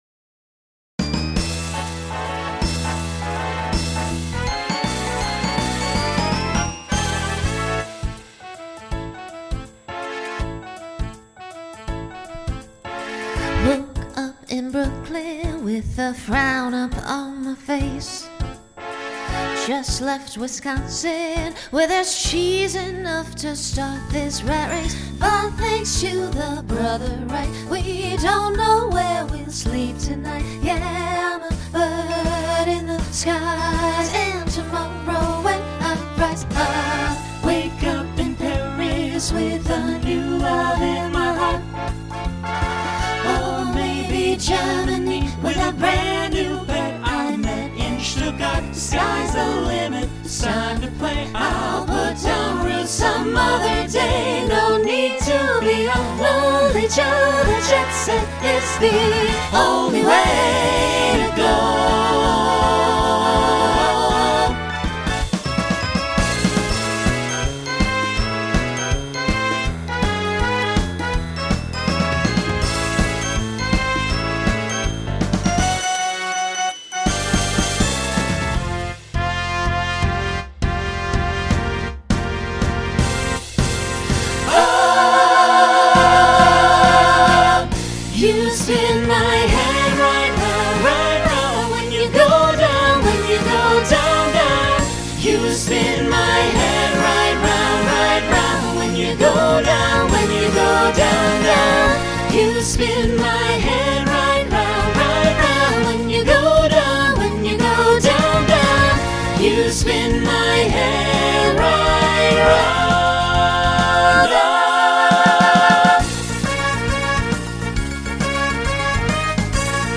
Broadway/Film , Rock Instrumental combo
Story/Theme Voicing SATB